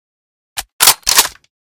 M24 and M98B Reanimation / gamedata / sounds / weapons / librarian_m24 / bolt.ogg
bolt.ogg